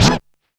ORCH SCRATCH.wav